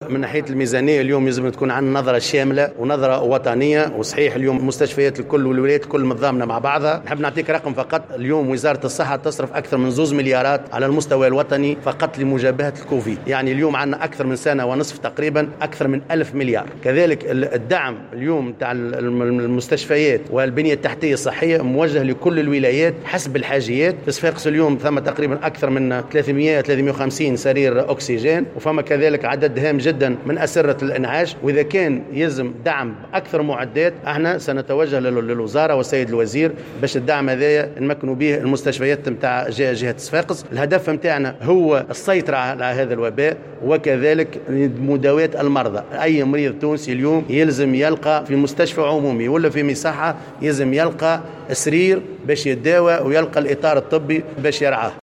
أكد رئيس لجنة الصحة بالبرلمان العياشي زمال، في تصريح لمراسل "الجوهرة أف أم" بصفاقس اليوم الثلاثاء، ضرورة توفير المعدات الطبية اللازمة لمجابهة كورونا في جميع الولايات منها ولاية صفاقس.